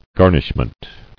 [gar·nish·ment]